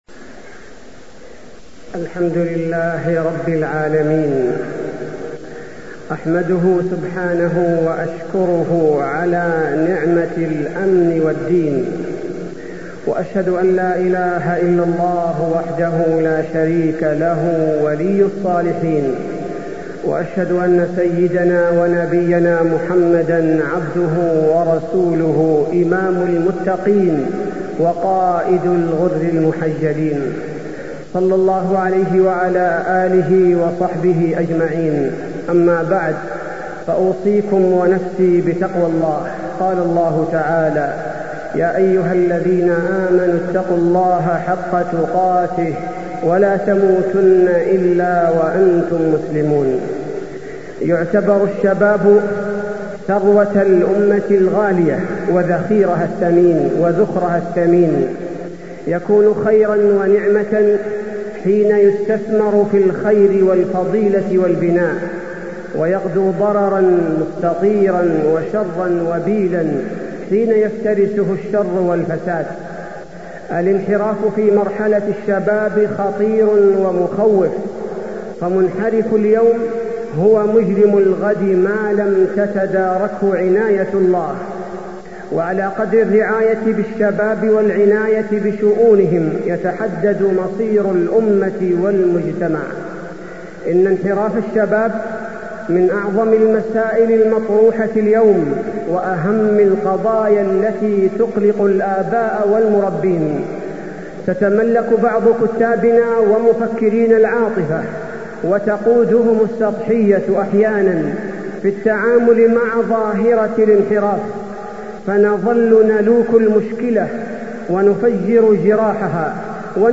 تاريخ النشر ٢٧ جمادى الآخرة ١٤٢٥ هـ المكان: المسجد النبوي الشيخ: فضيلة الشيخ عبدالباري الثبيتي فضيلة الشيخ عبدالباري الثبيتي الانحراف